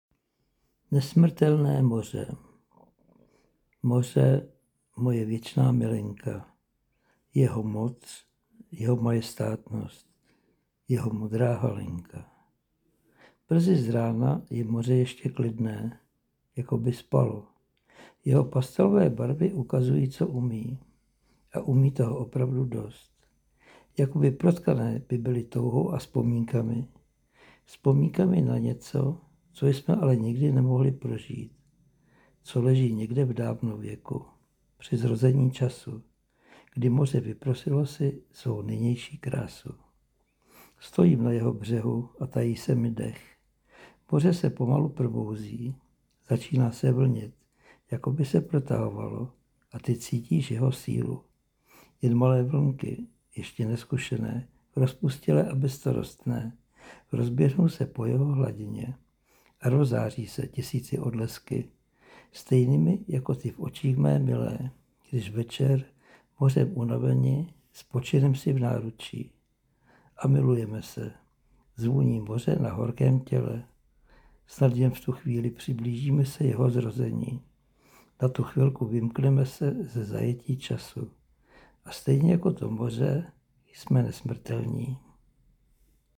Tvé dílo v pěkné v popisnosti a tvůj mužný "chraplák" to povyšuje k romantickým představám.